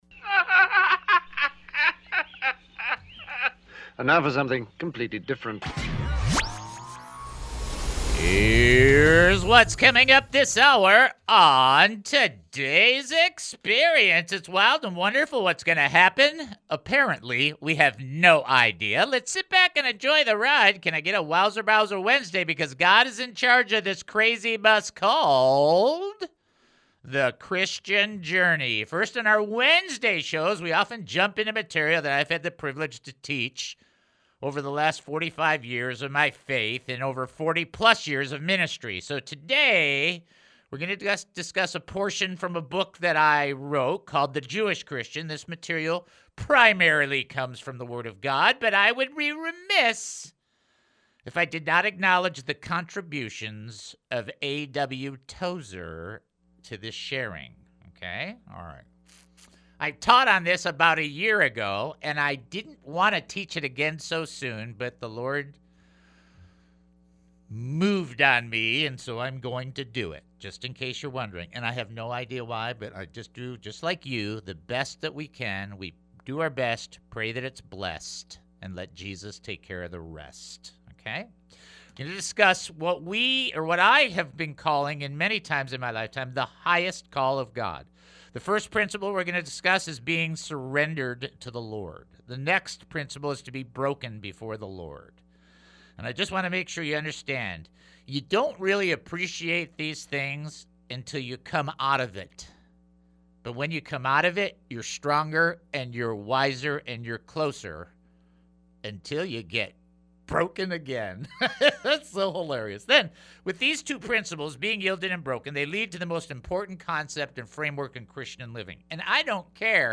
1) Phone calls, prayers, and trivia make up the core of this show, aiming to help people realize the benefits of being close to the Lord God Almighty.